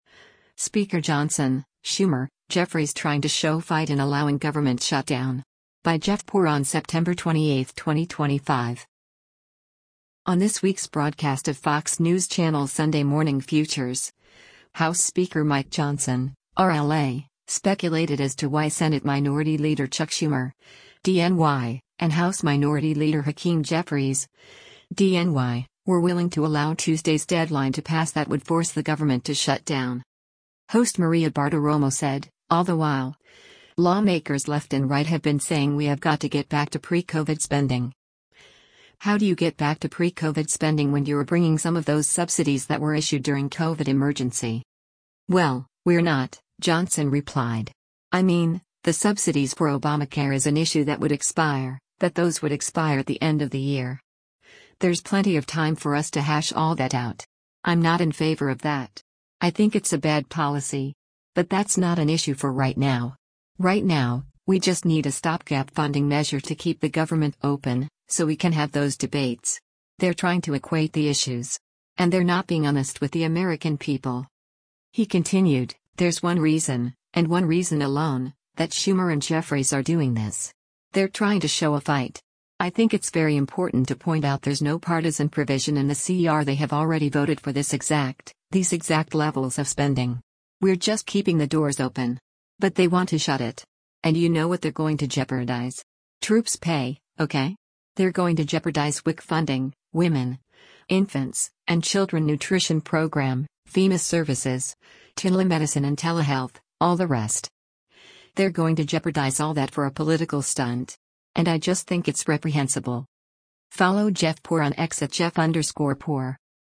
On this week’s broadcast of Fox News Channel’s “Sunday Morning Futures,” House Speaker Mike Johnson (R-LA) speculated as to why Senate Minority Leader Chuck Schumer (D-NY) and House Minority Leader Hakeem Jeffries (D-NY) were willing to allow Tuesday’s deadline to pass that would force the government to shut down.